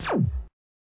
laser2.mp3